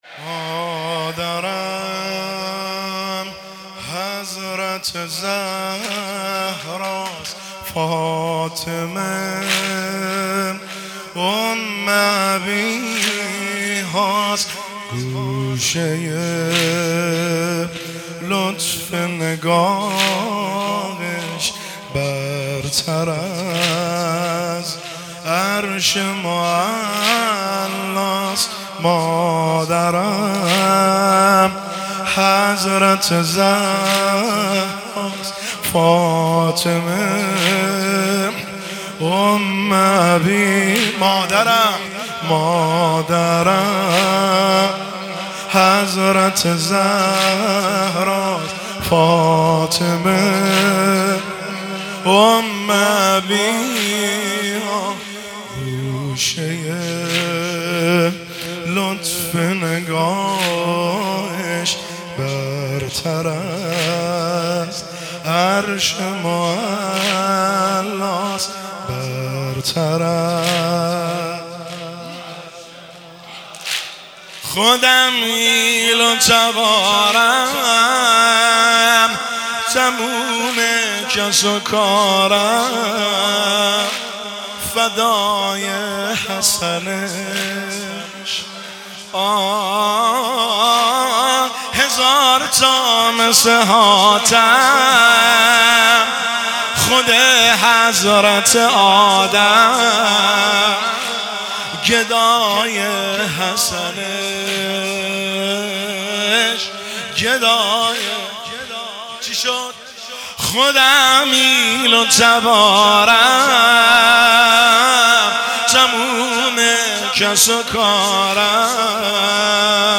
مداحی واحد
حسینیه چهارده معصوم شهر ری